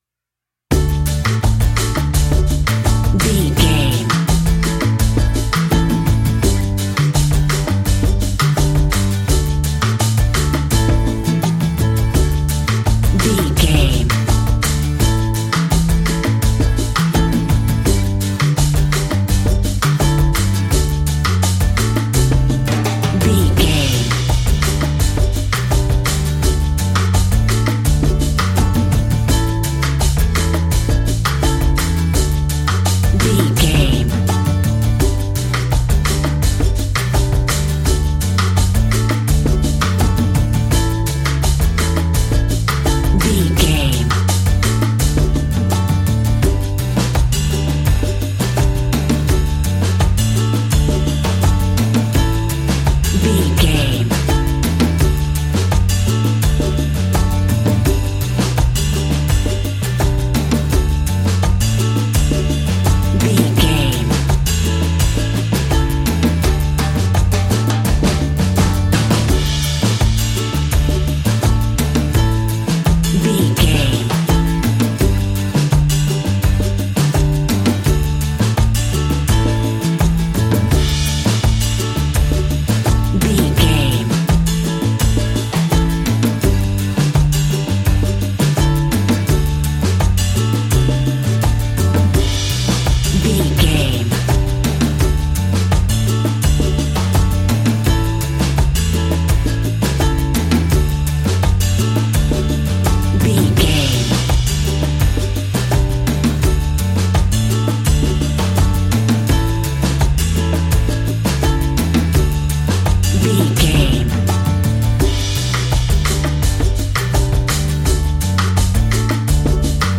Ionian/Major
cheerful/happy
mellow
drums
electric guitar
percussion
horns
electric organ